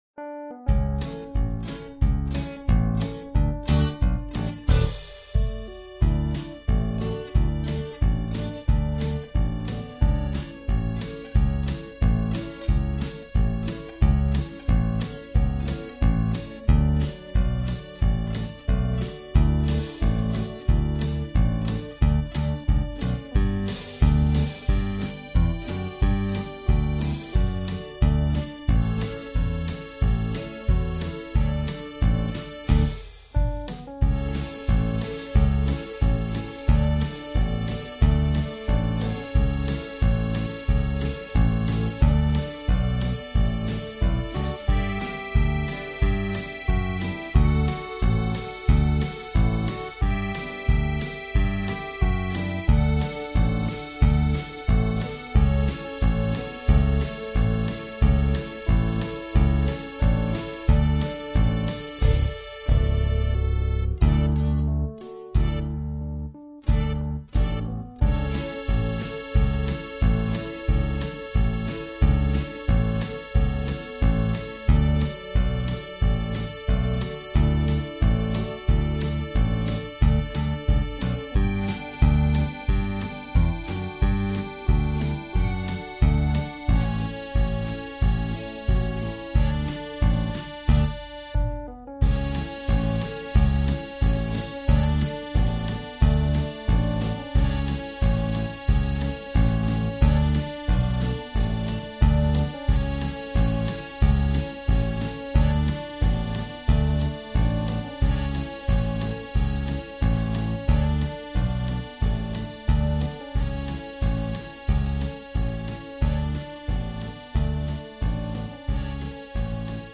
File audio de base dal cjant
base-musicâl.mp3